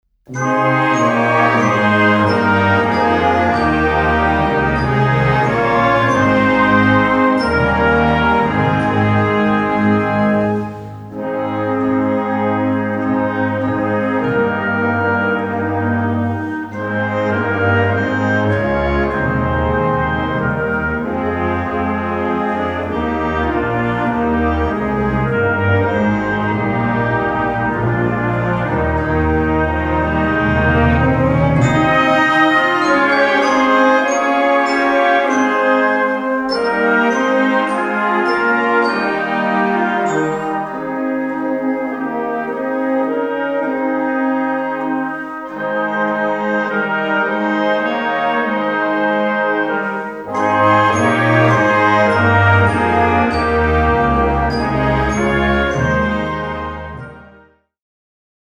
A4 Besetzung: Blasorchester PDF